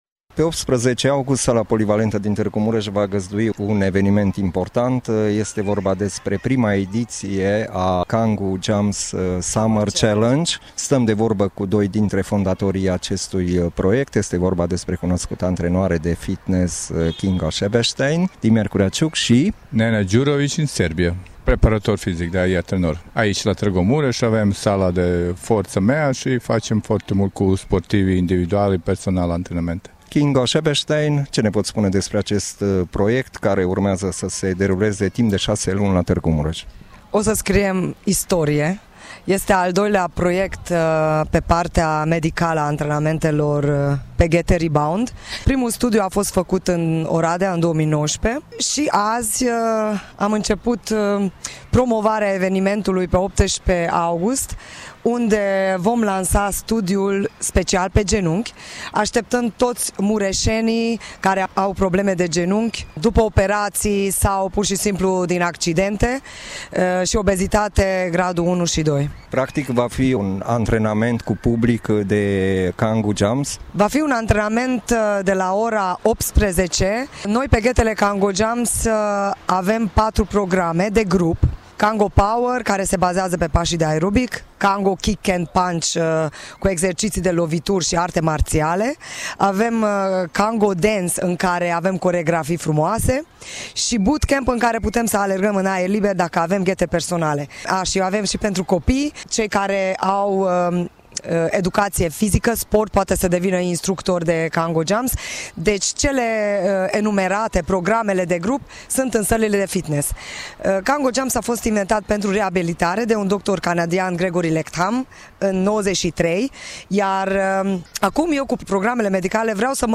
interviul audio